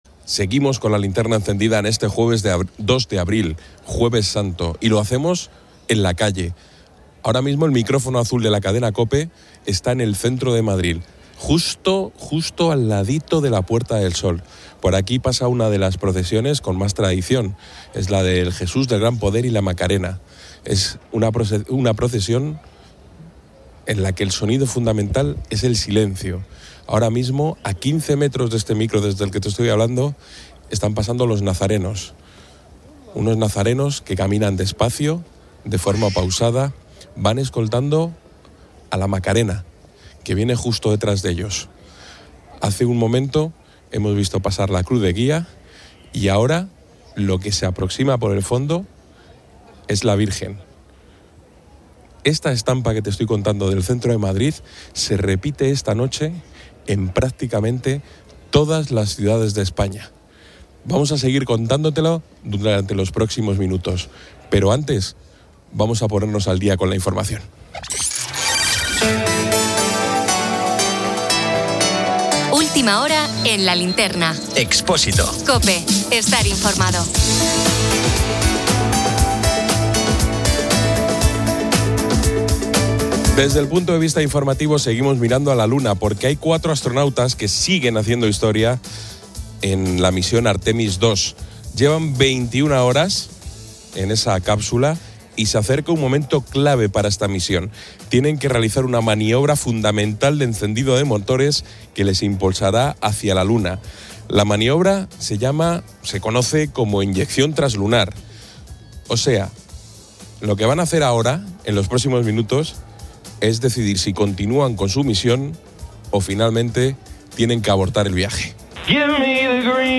El programa narra en directo el Jueves Santo en España, mostrando la diversidad de celebraciones.